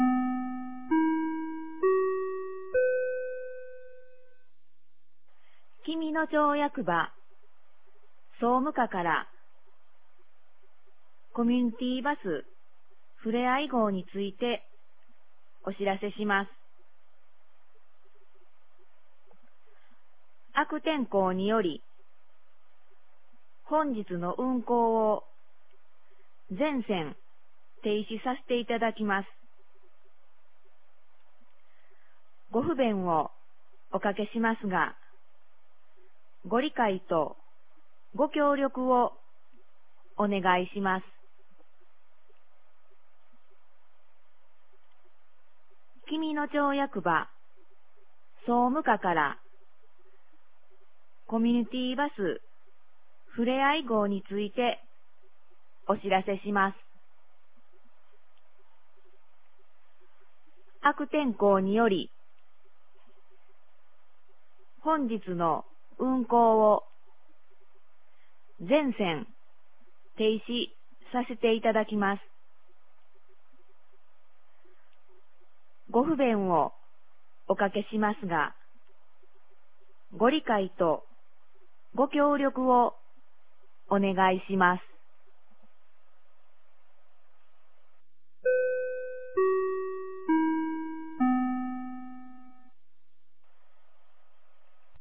2023年06月02日 12時06分に、紀美野町より全地区へ放送がありました。
放送音声